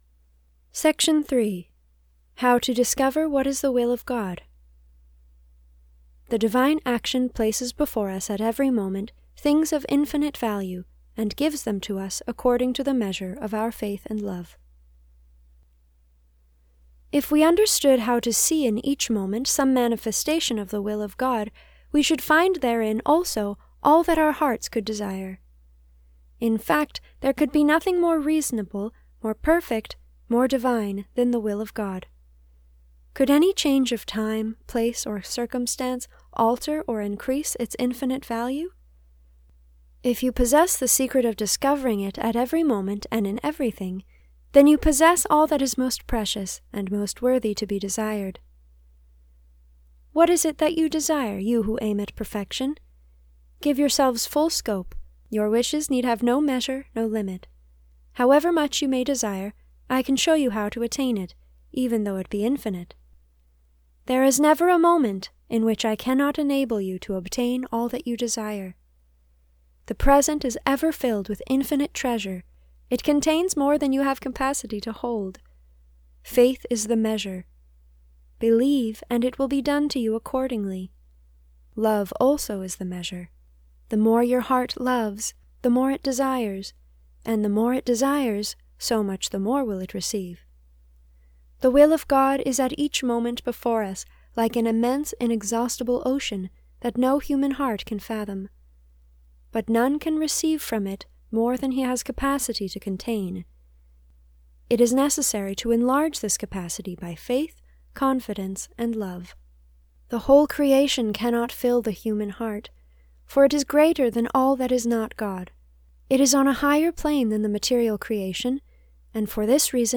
This is a reading from the spiritual classic Abandonment to Divine Providence by Jean Pierre de Caussade (1675 - 1751).